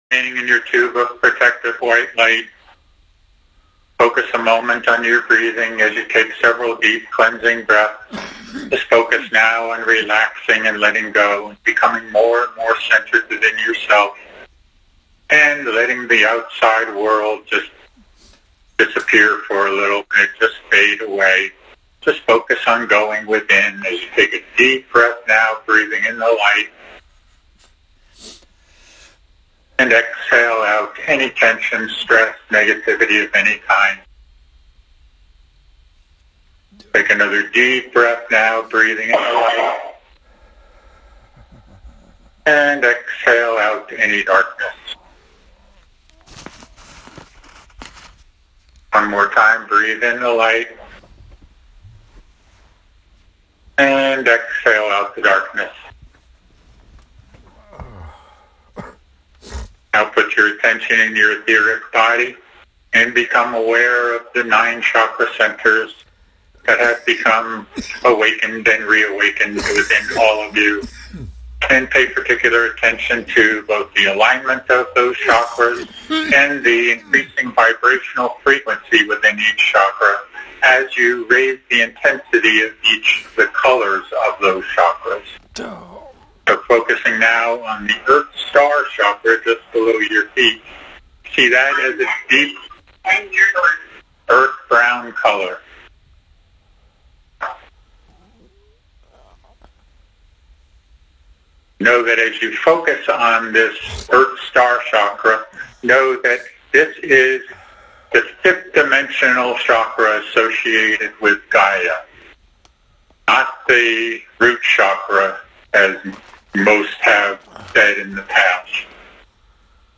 These messages were given during our Ancient Awakenings weekly Sunday conference call in Payson, AZ on November 9, 2025.
Meditation – Minute (00:00) Follow along and join-in group meditation with Lord Sananda.